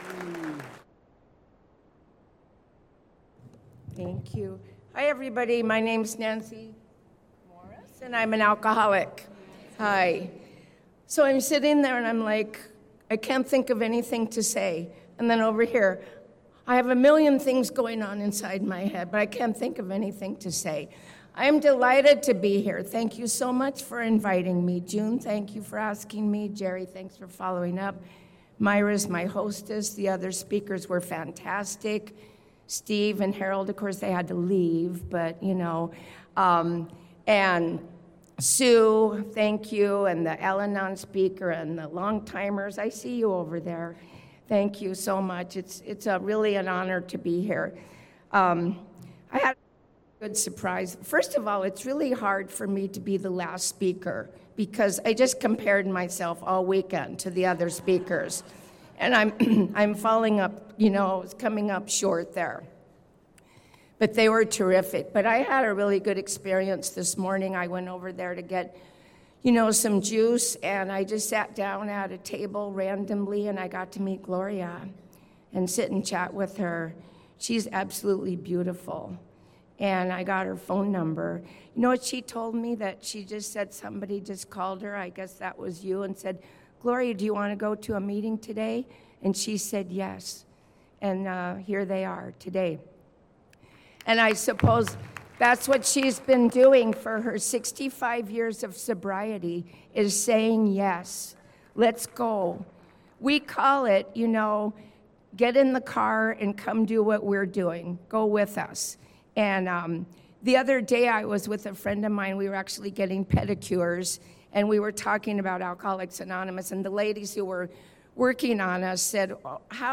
Sunday Morning AA Speaker &#8211
Seal Beach